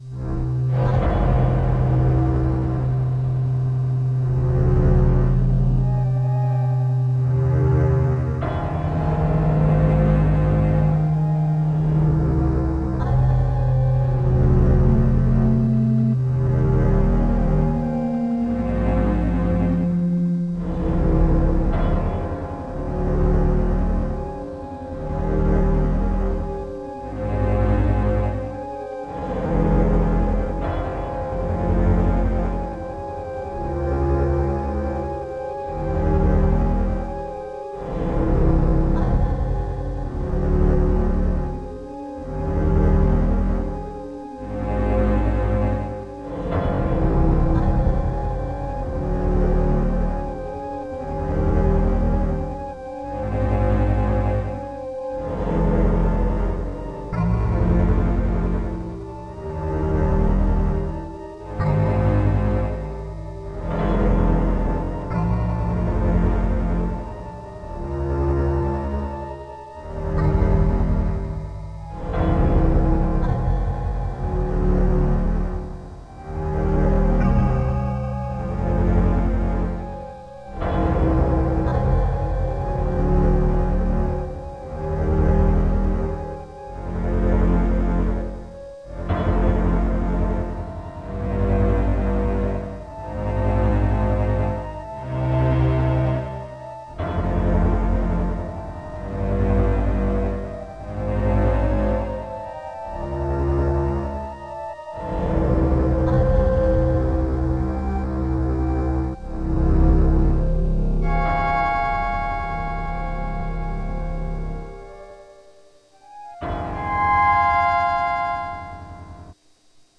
Telos System Ruins background music for video game Im making (ambient type tune)